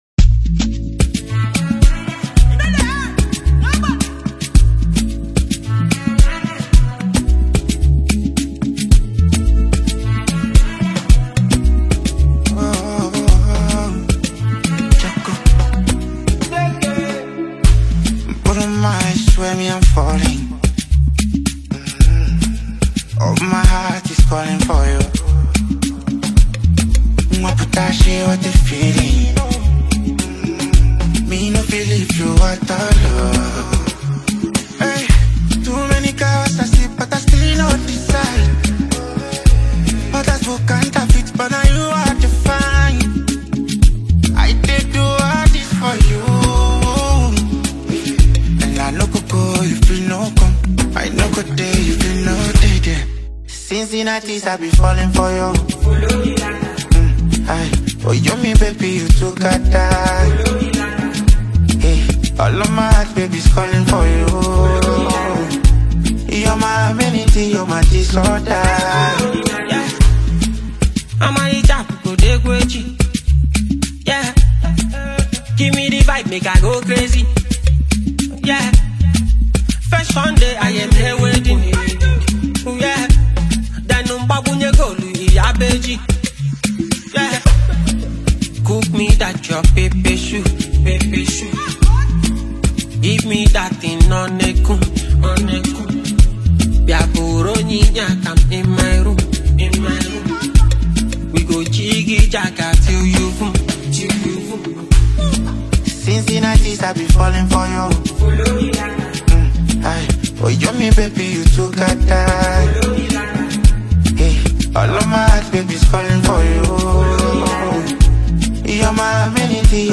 Street motivation meets pure vibe
delivers with passion and hunger
smooth energy that keeps the rhythm alive